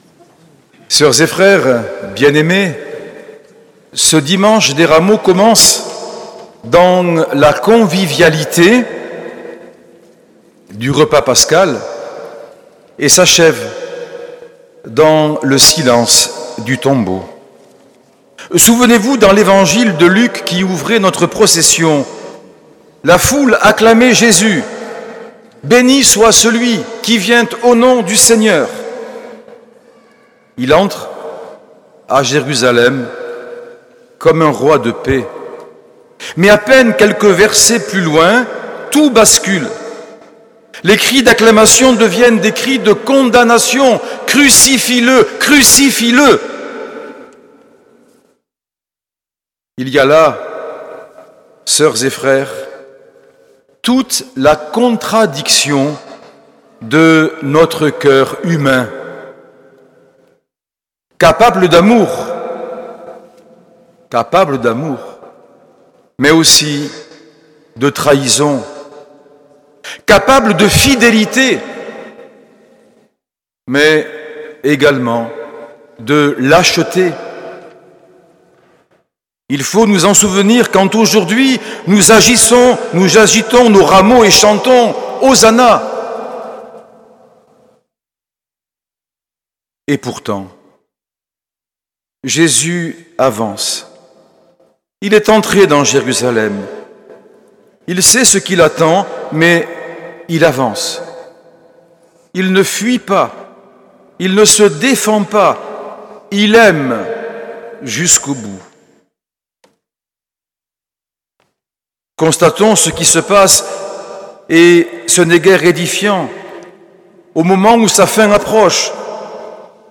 Homélie de Monseigneur Norbert TURINI, dimanche 13 avril 2025, messe des Rameaux et de la Passion du Seigneur